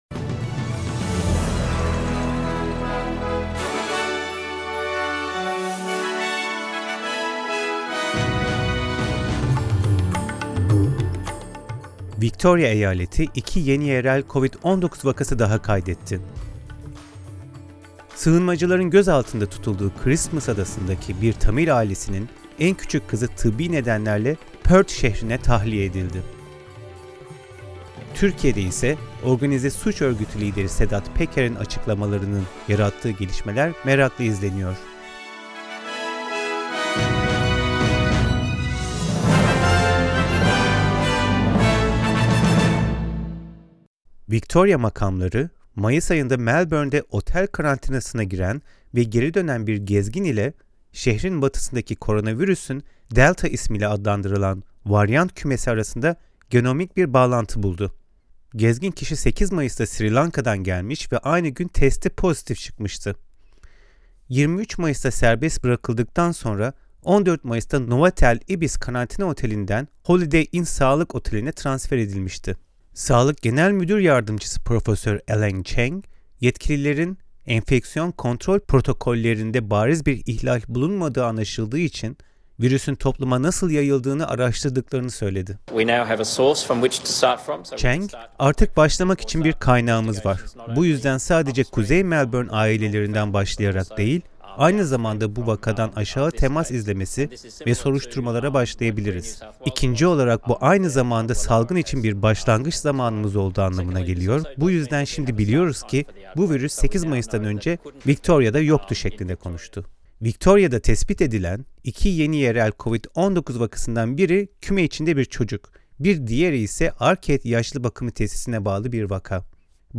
SBS Türkçe Haberler 8 Haziran